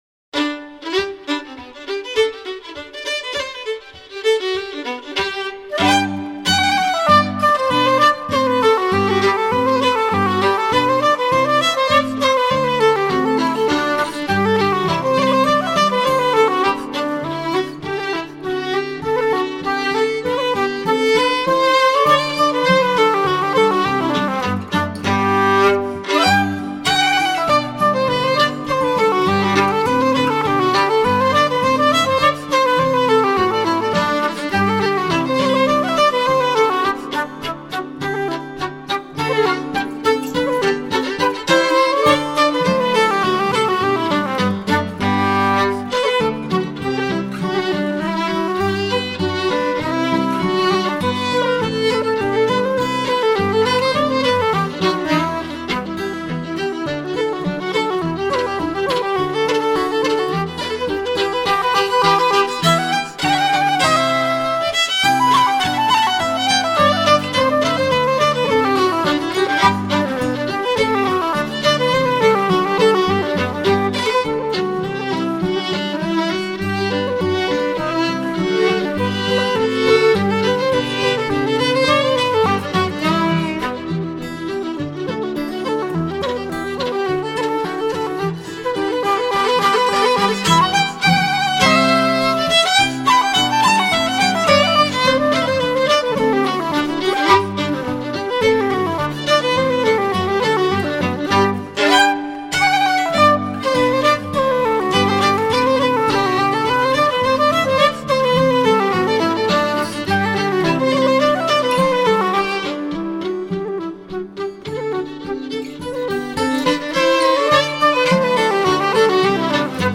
喜欢的4首爱尔兰风笛曲 激动社区，陪你一起慢慢变老！